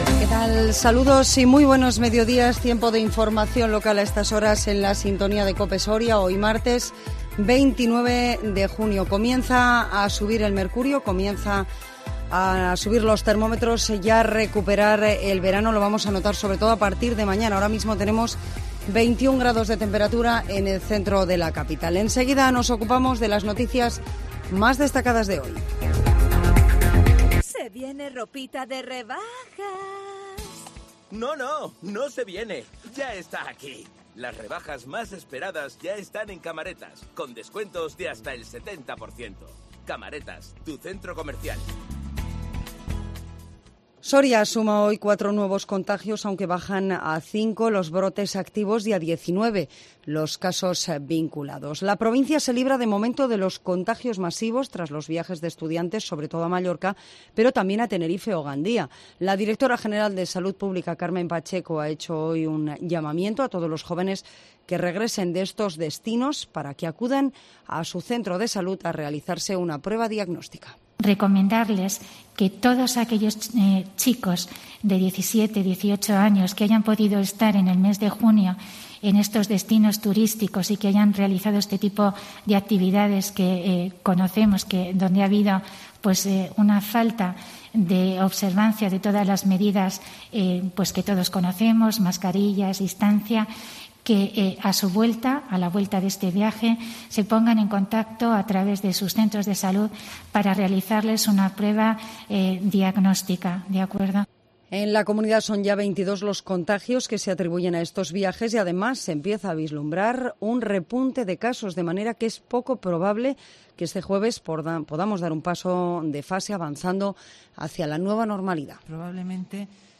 INFORMATIVO MEDIODÍA 29 JUNIO 2021